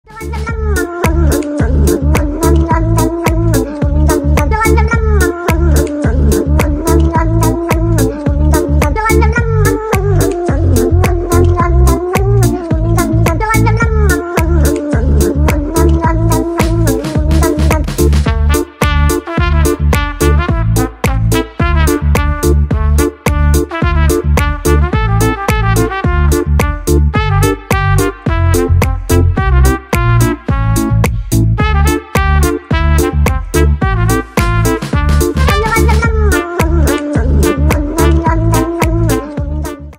Весёлые Рингтоны